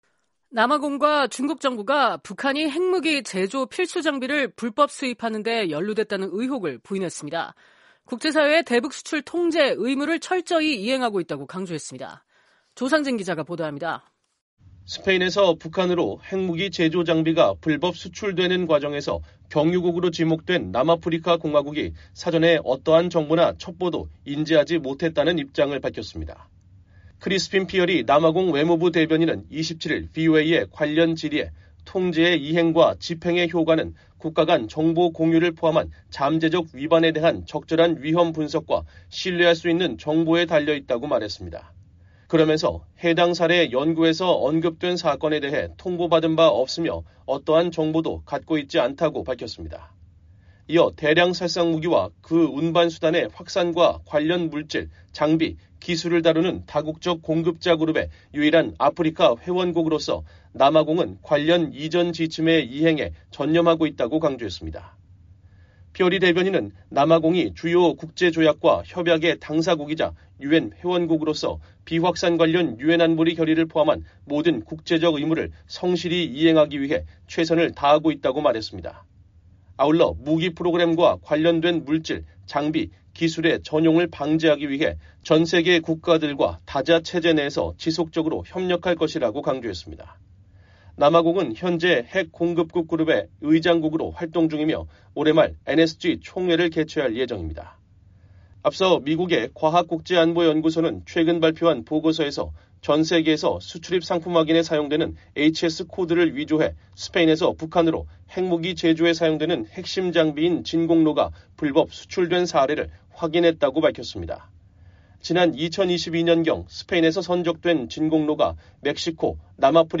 속보